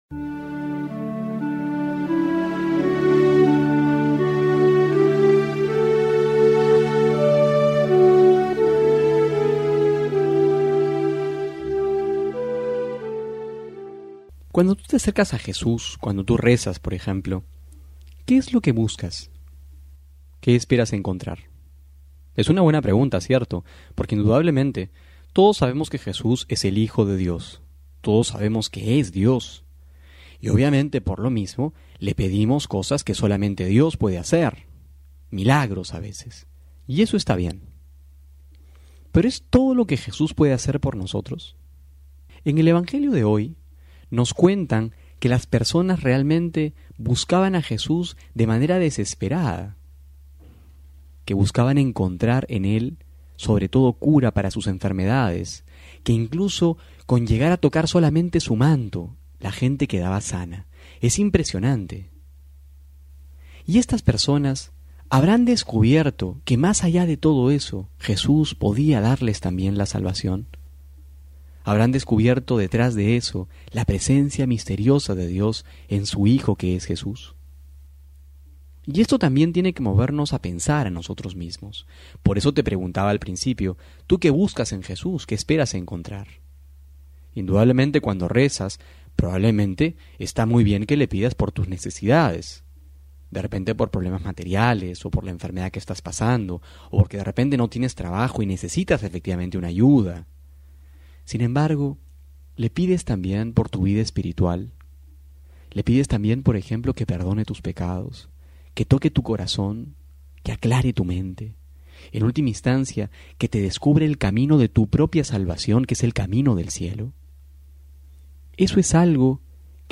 Homilía para hoy: Marcos 6,53-56
febrero06-12homilia.mp3